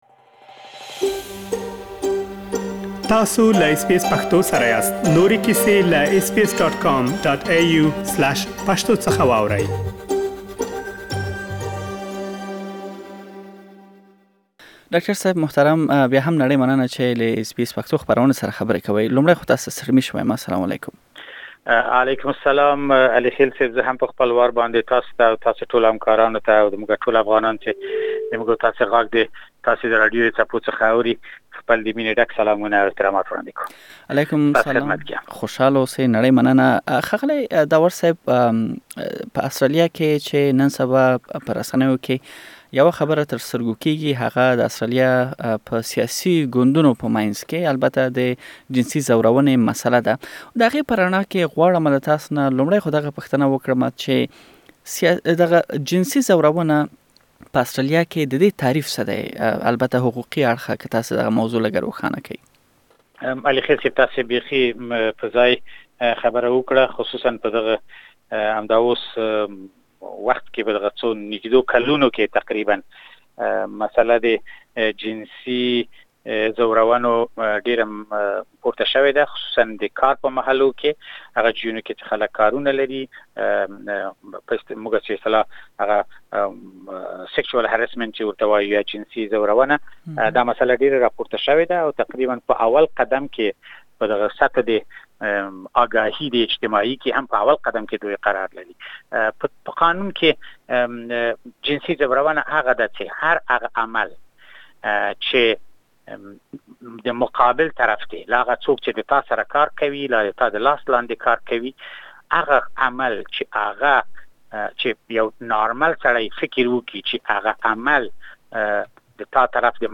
دا ټول بشپړه مرکه کې واورئ.